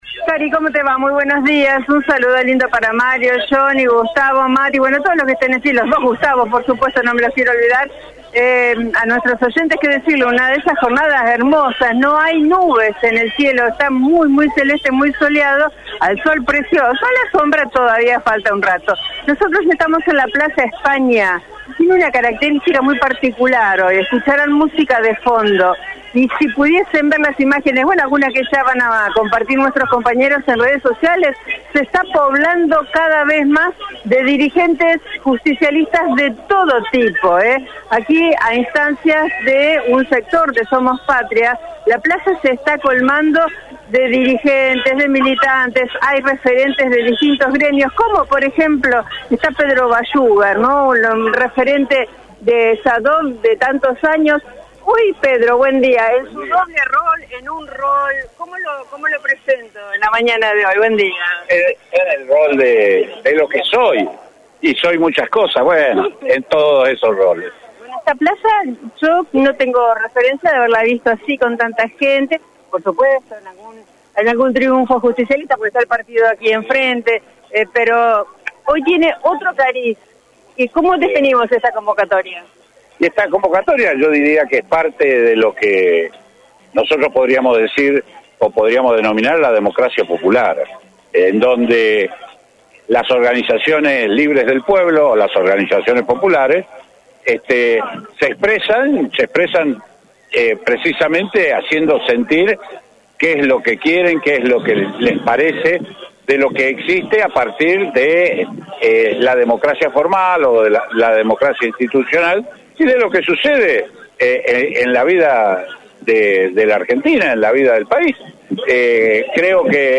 La convocatoria se concretó hoy poco después de las 10 en plaza España de la capital provincial, frente a la sede del PJ santafesino.